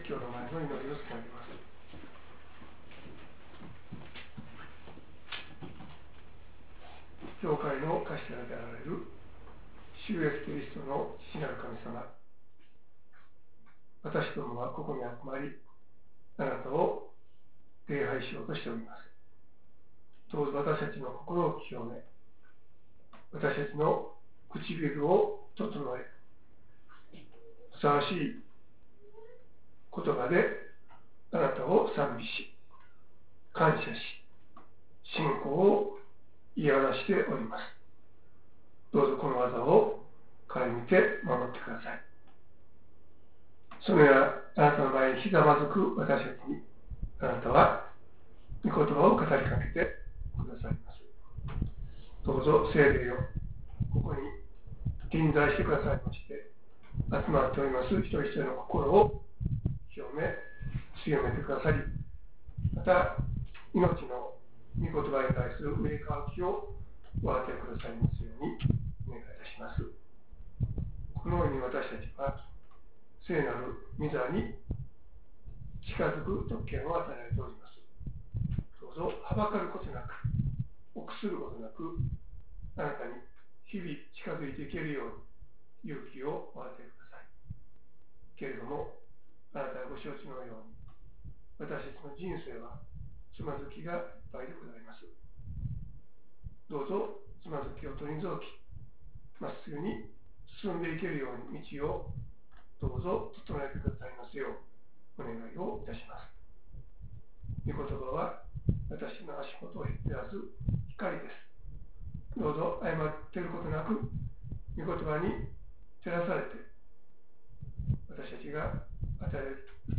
説教「聞く耳のある人は聞きなさい」